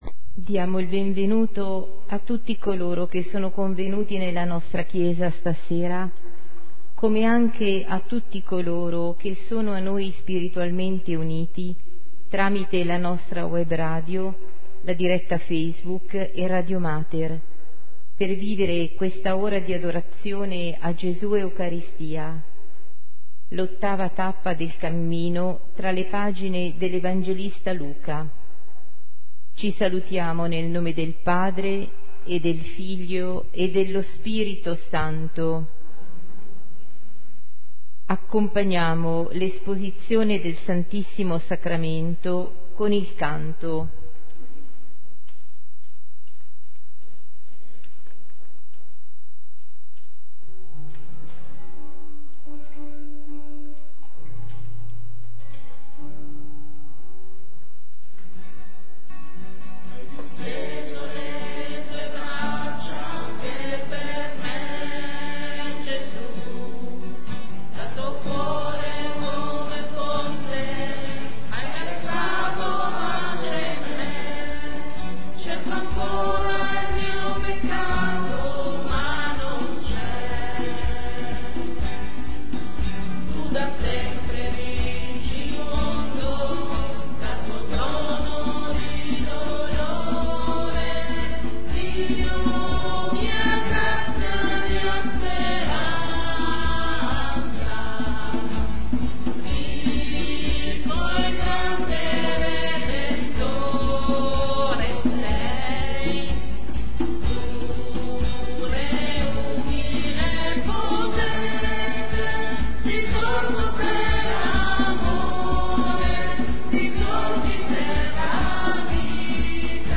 Adorazione a cura delle suore Adoratrici di Monza